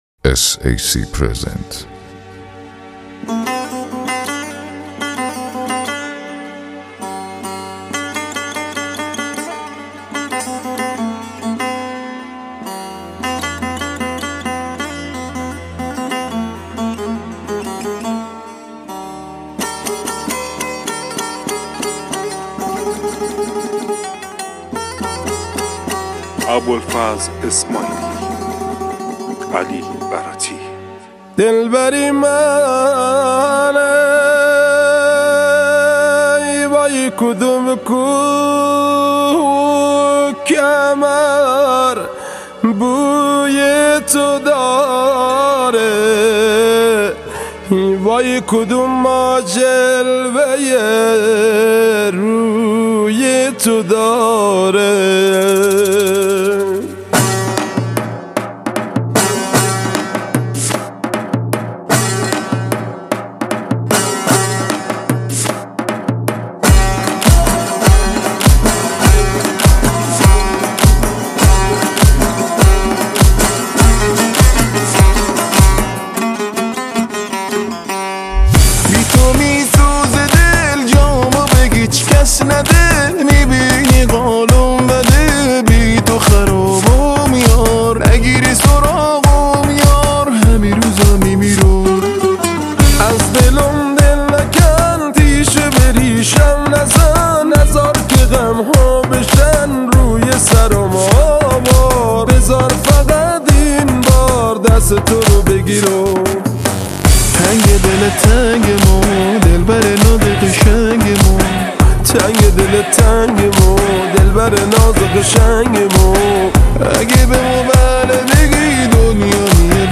دسته : پاپ